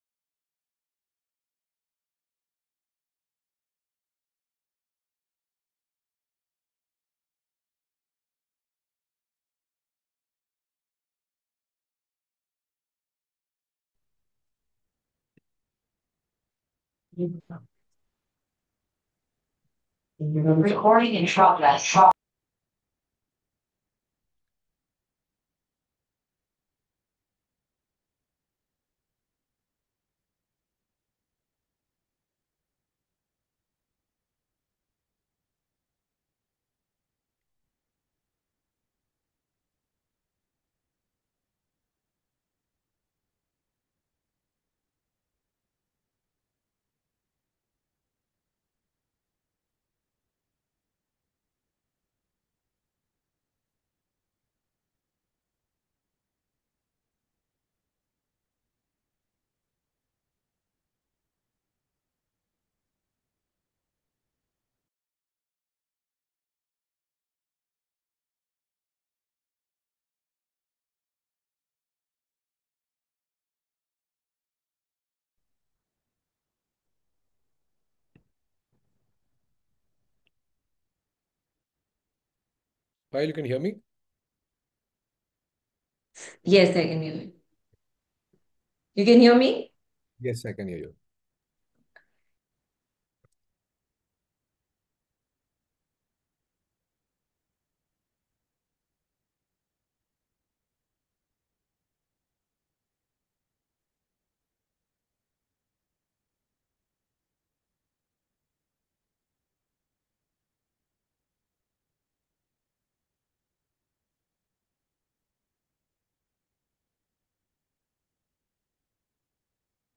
Recording of Veefin's Earnings Conference Call H2 FY2025.m4a